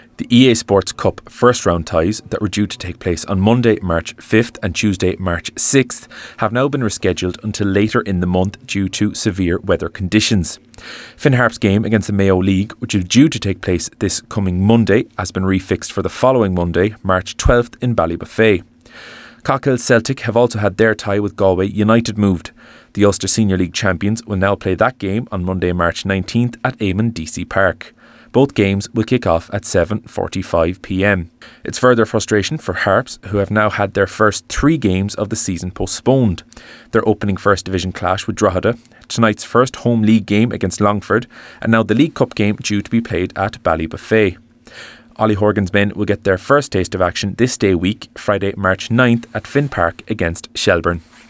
Finn Harps hit with another postponed game: Report